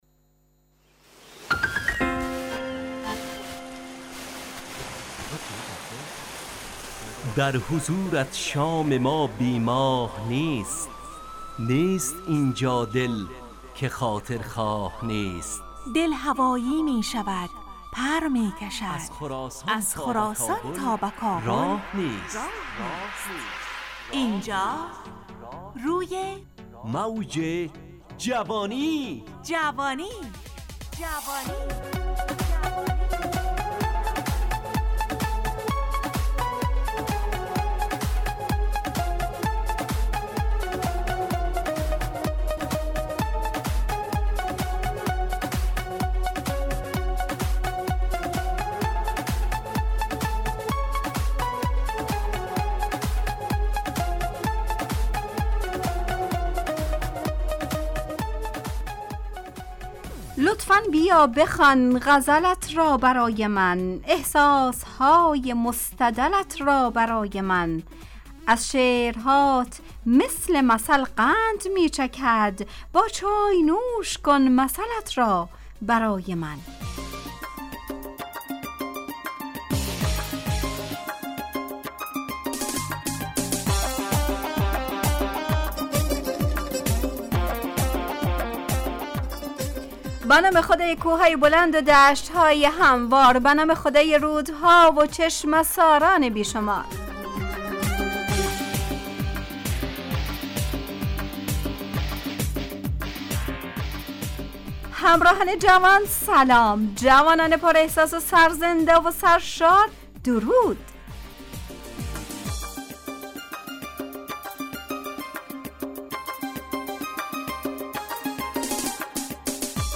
روی موج جوانی، برنامه شادو عصرانه رادیودری.
همراه با ترانه و موسیقی مدت برنامه 70 دقیقه .